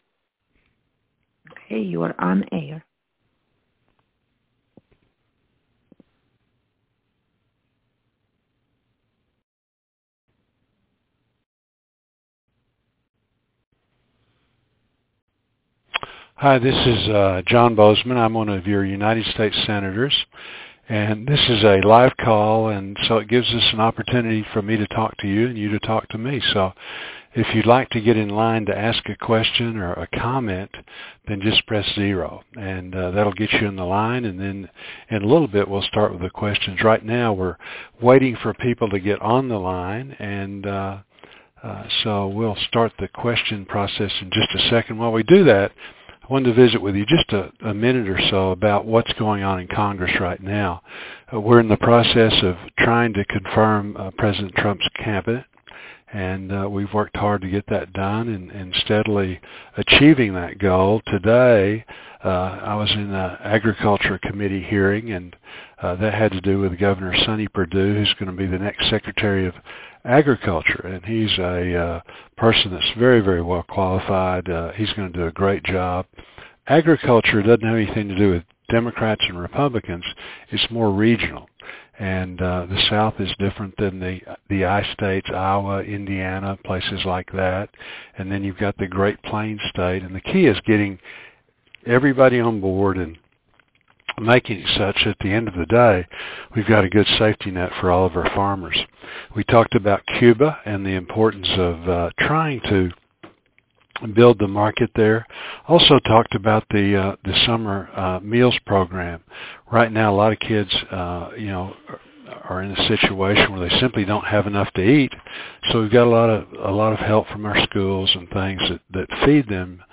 Listen to My Latest Telephone Town Hall